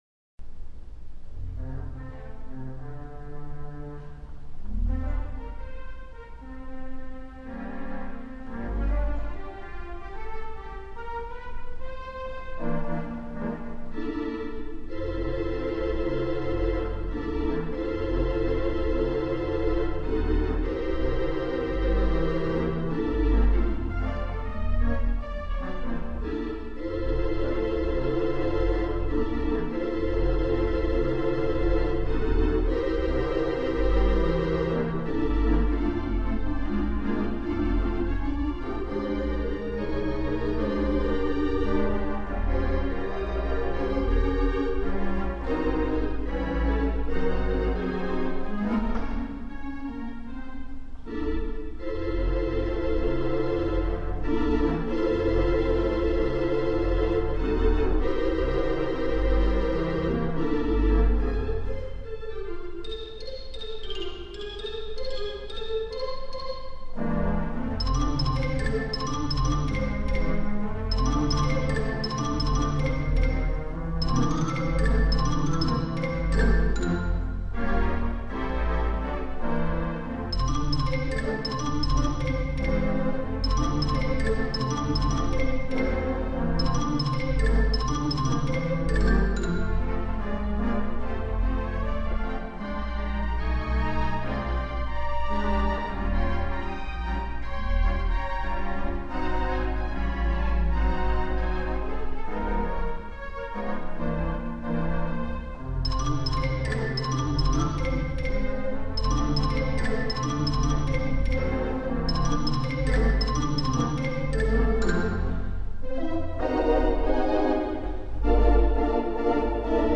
The Avalon Mighty WurliTzer